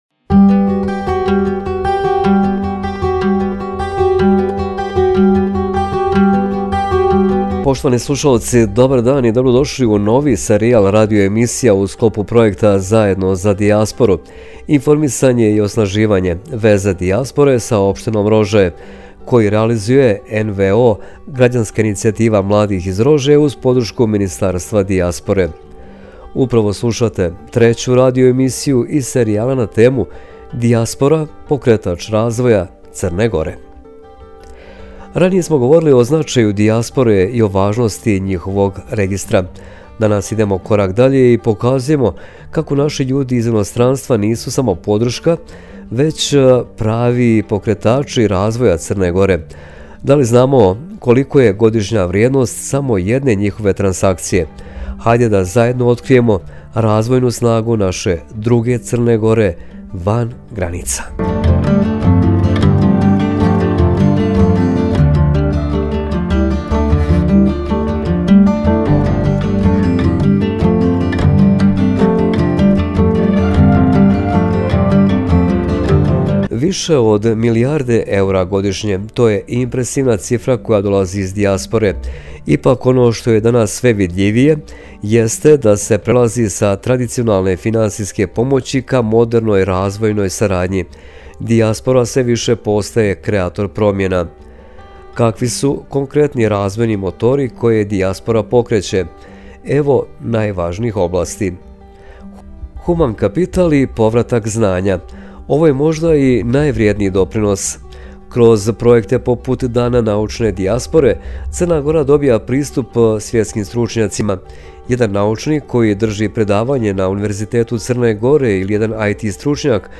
Slušate treću radio emisija u sklopu projekta “Zajedno za dijasporu: Informisanje i osnaživanje – veza dijaspore sa opštinom Rožaje” koji realizuje NVO ”Građanska inicijativa mladih” iz Rožaja, uz podršku Ministarstva dijaspore: “Dijaspora – pokretač razvoja Crne Gore”